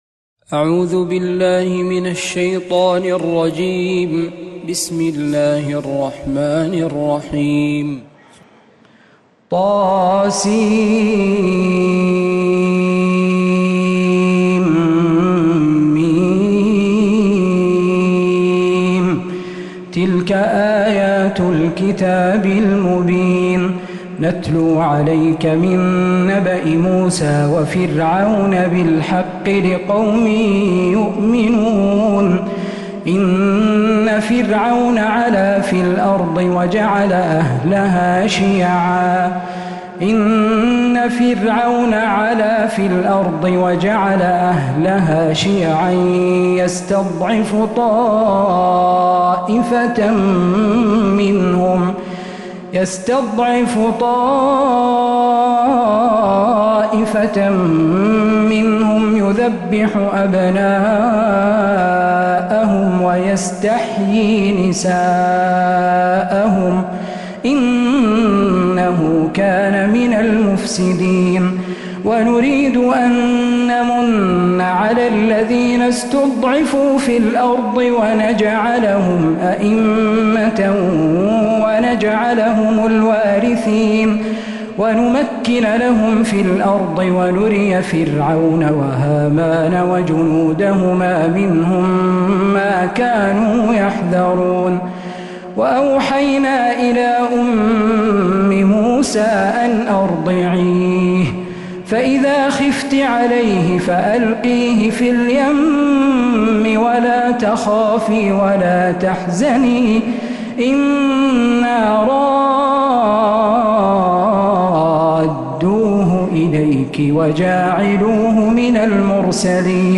من تراويح و فجريات الحرم النبوي